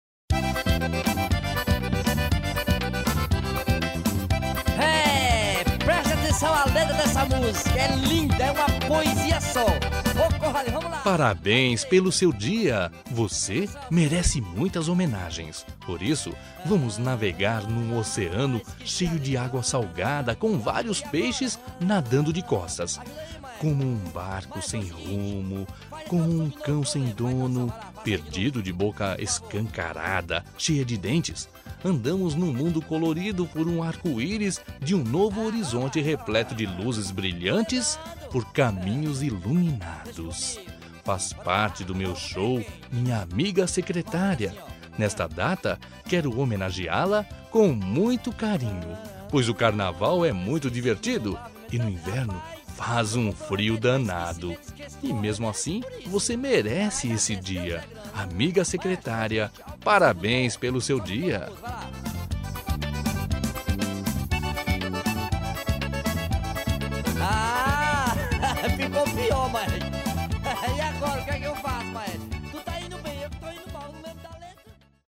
Aniversário de Humor – Voz Masculina – Cód: 200215